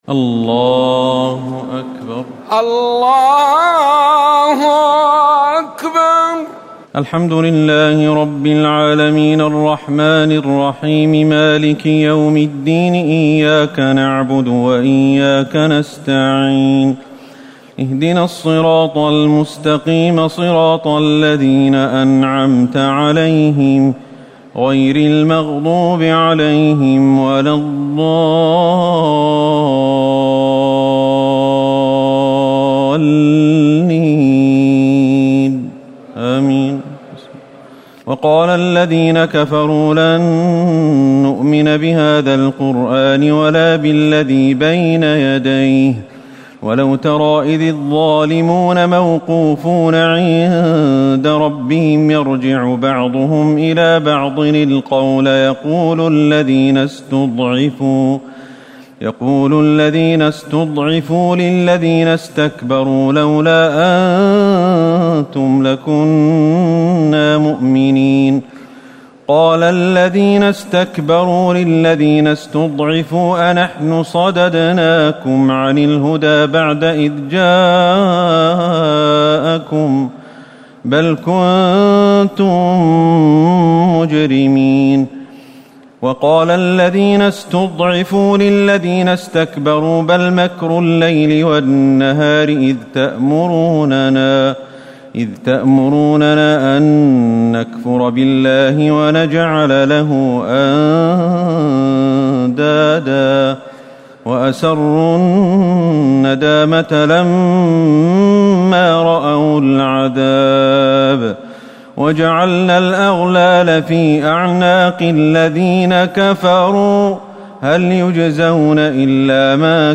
تراويح ليلة 21 رمضان 1439هـ من سور سبأ (31-54) وفاطر و يس(1-30) Taraweeh 21 st night Ramadan 1439H from Surah Saba and Faatir and Yaseen > تراويح الحرم النبوي عام 1439 🕌 > التراويح - تلاوات الحرمين